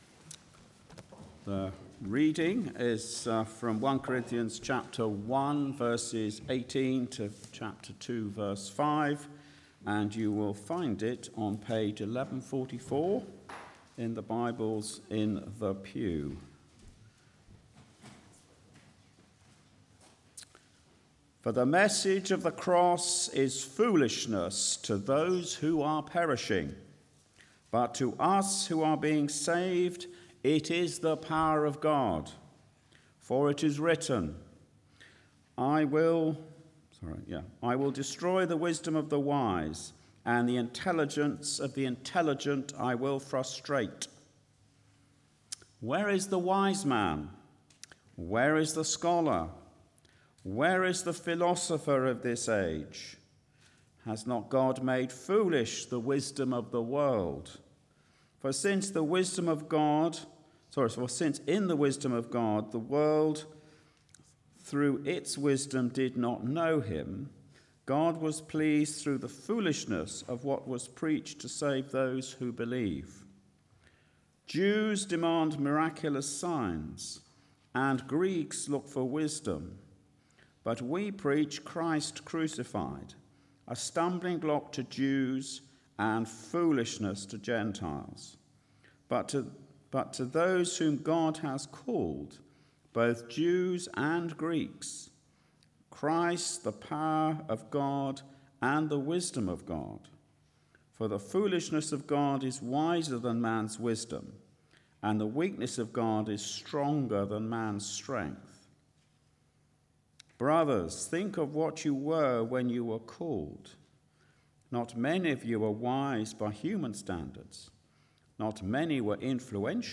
Media for Arborfield Morning Service on Sun 07th May 2023 10:00
Theme: Sermon